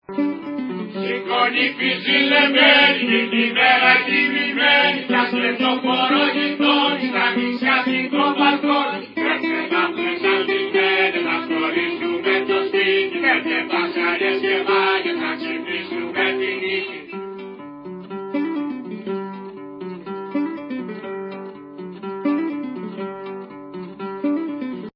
Ηχητική μπάντα παράστασης
τραγούδι